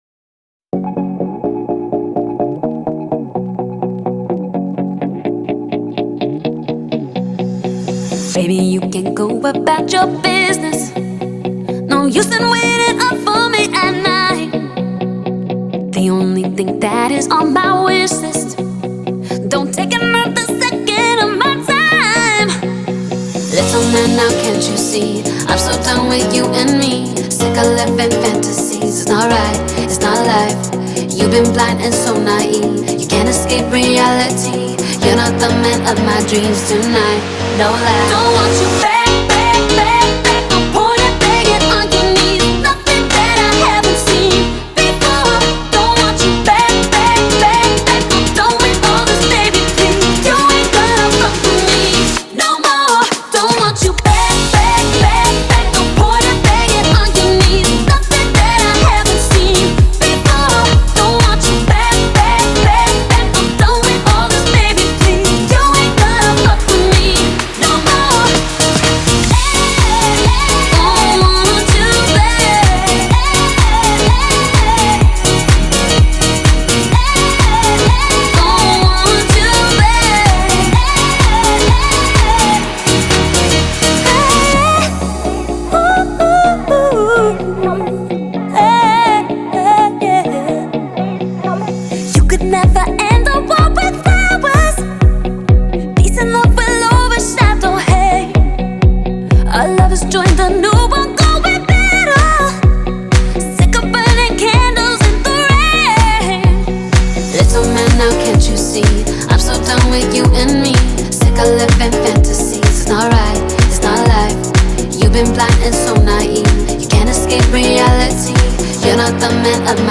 C5 = 528Hz